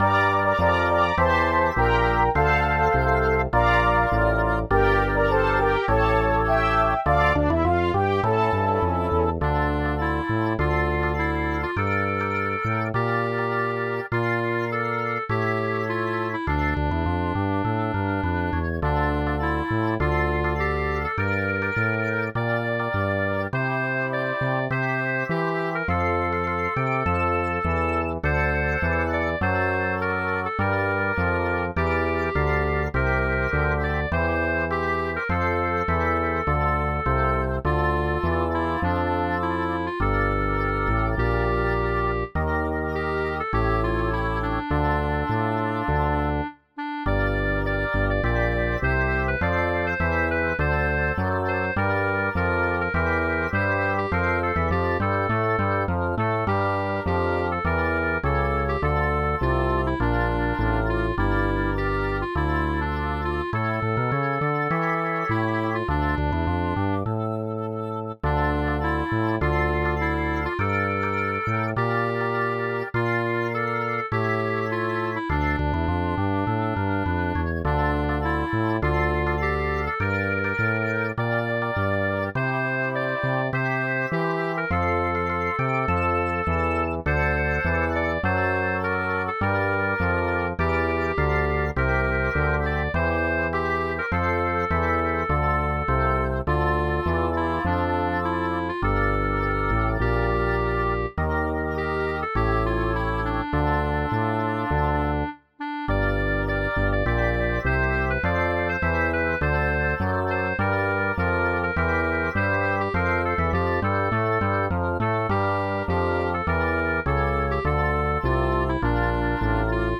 Стройова пісня! 12 12 12 friends friends hi